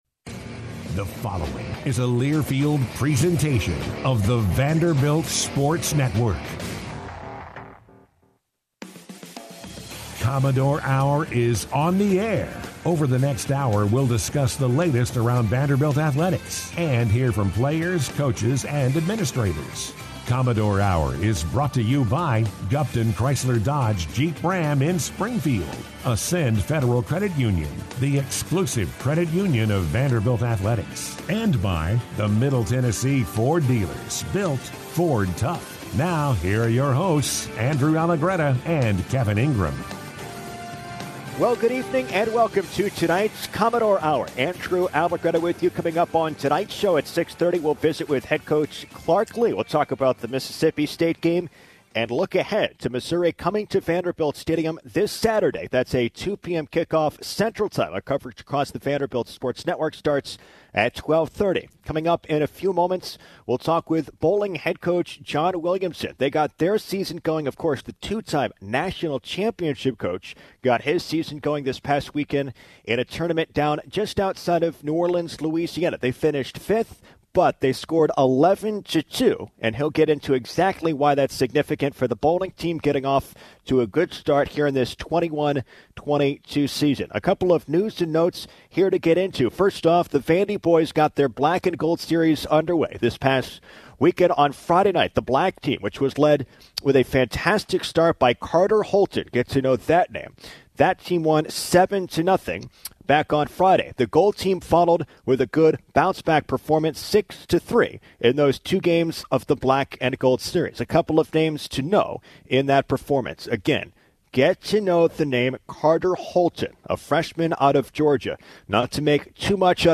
Guests on this week's Commodore Hour, Monday nights from 6-7 on ESPN 94.9: